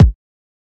00s Subtle Reverb Kick Drum Single Hit A# Key 57.wav
Royality free kick tuned to the A# note. Loudest frequency: 204Hz
.WAV .MP3 .OGG 0:00 / 0:01 Type Wav Duration 0:01 Size 52,05 KB Samplerate 44100 Hz Bitdepth 16 Channels Mono Royality free kick tuned to the A# note.
00s-subtle-reverb-kick-drum-single-hit-a-sharp-key-57-k0Y.ogg